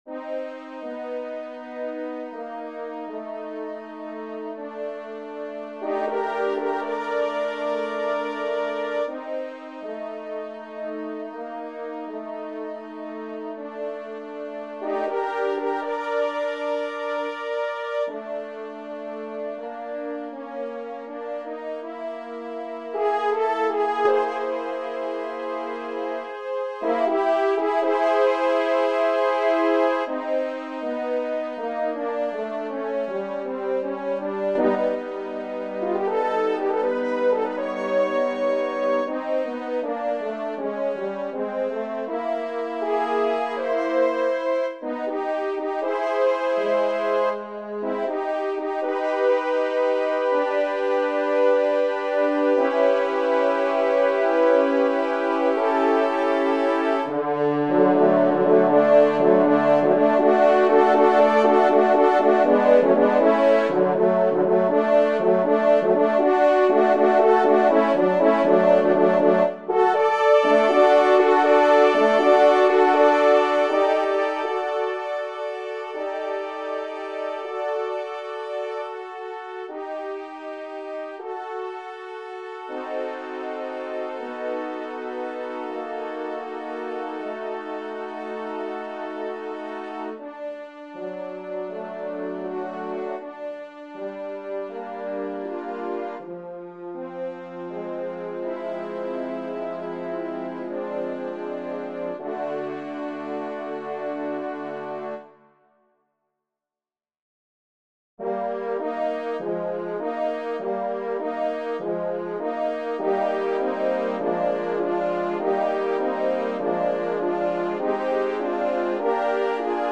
Movements/SectionsMov'ts/Sec's 7 movements
Piece Style Modern
Instrumentation 4 horns